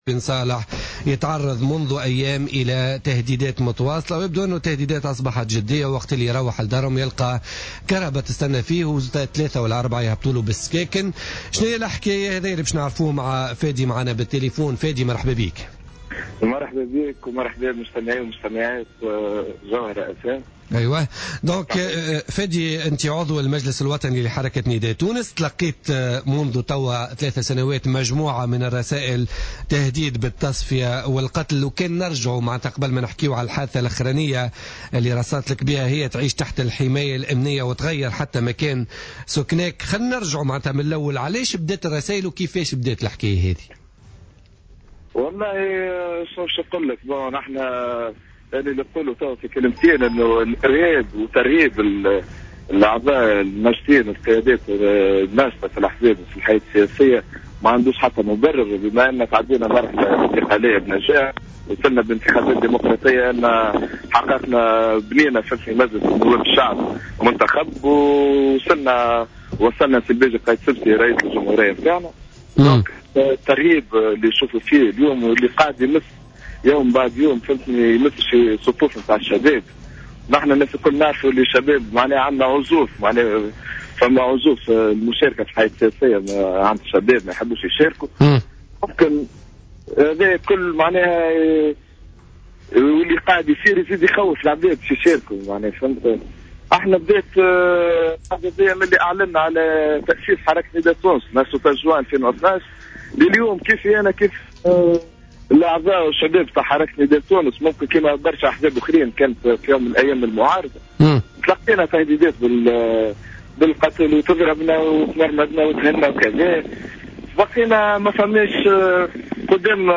lors de son passage sur les ondes de Jawhara Fm